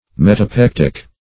Search Result for " metapectic" : The Collaborative International Dictionary of English v.0.48: Metapectic \Met`a*pec"tic\, a. [Pref. meta- + pectic.]